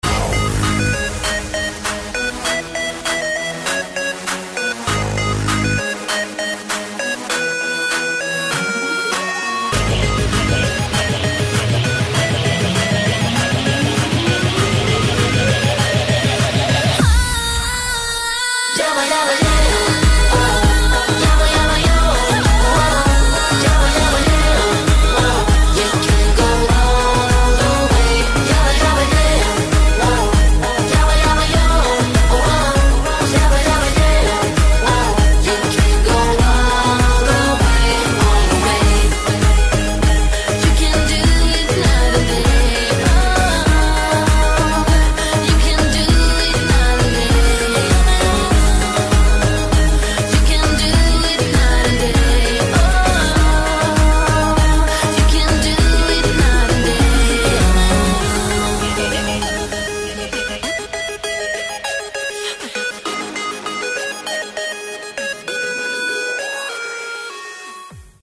ритмичные
мужской вокал
громкие
женский вокал
dance
Electronic
EDM
электронная музыка